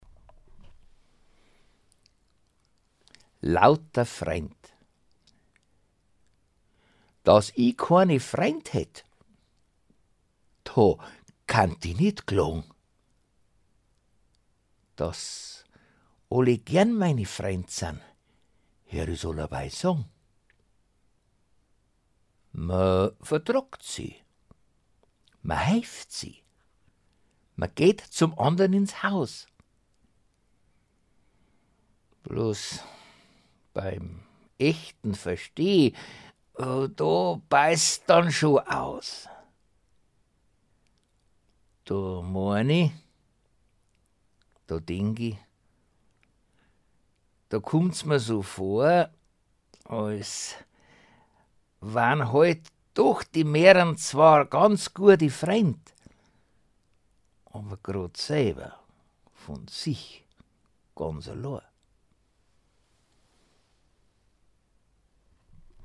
Rezitation
Flöte
Gitarre
Die technischen Mängel mag man übersehen, es war eine Spontanaufnahme